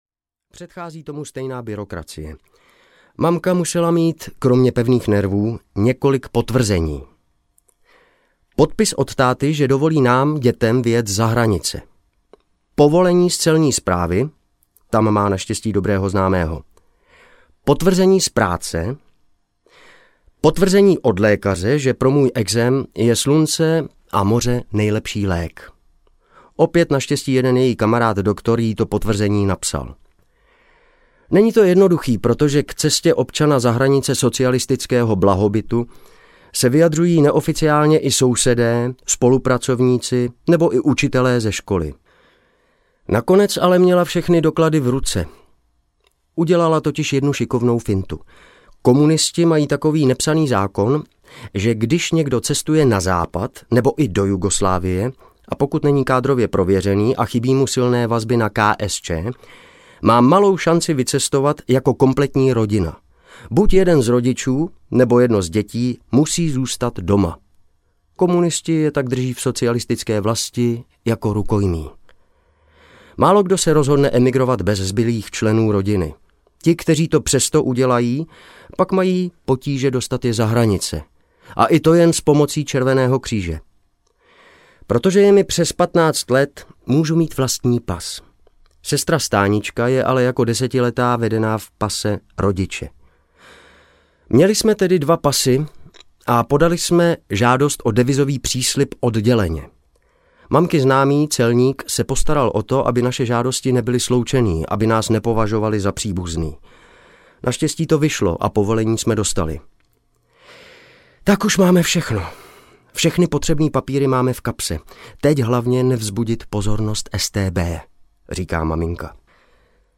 A zalévej kytky! aneb Příběh uprchlíka audiokniha
Ukázka z knihy
• InterpretPavel Batěk, Simona Postlerová